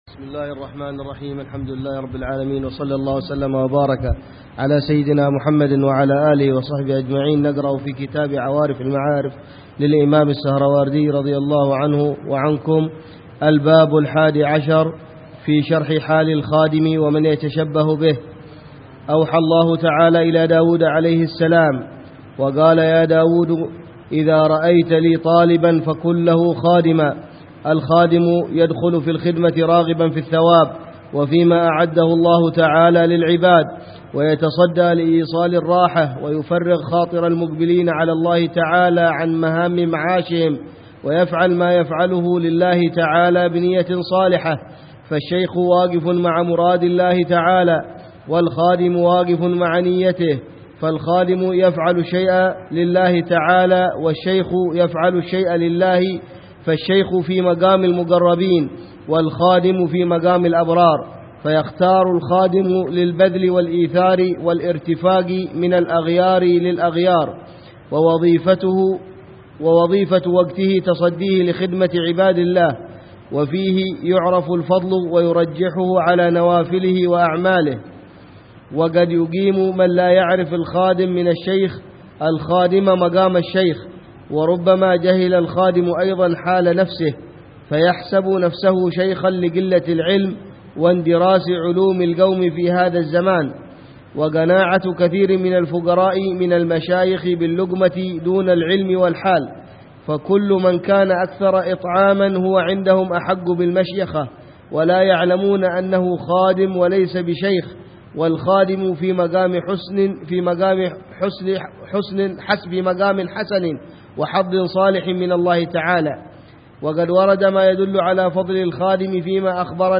شرح كتاب عوارف المعارف - الدرس الرابع عشر - شرح حال الخادم ومن يتشبه به
شرح لكتاب عوارف المعارف للإمام السهروردي ضمن دروس الدورة التعليمية الثانية عشرة والثالثة عشرة بدار المصطفى في صيف عامي 1427هـ و 1428هـ ..